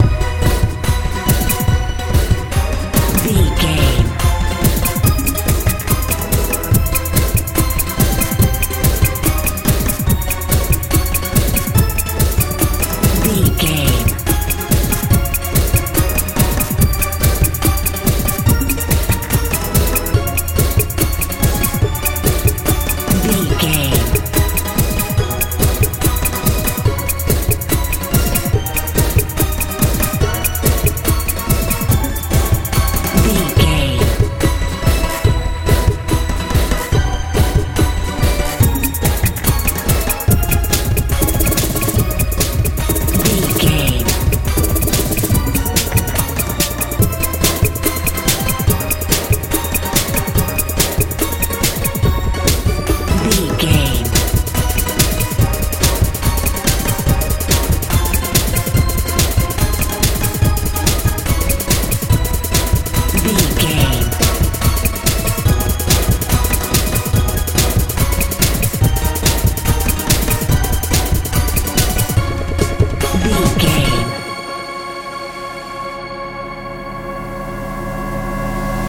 modern dance
Ionian/Major
A♭
energetic
powerful
synthesiser
bass guitar
drums
80s
90s
tension
suspense